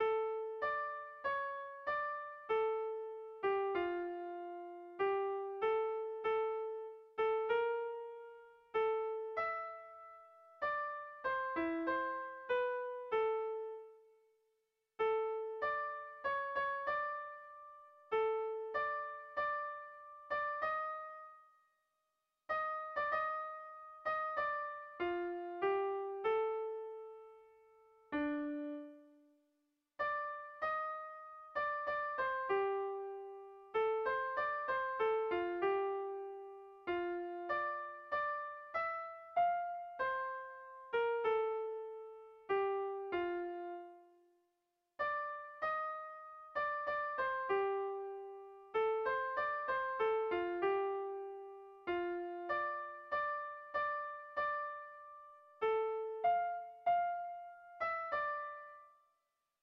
Erlijiozkoa
ABD1D2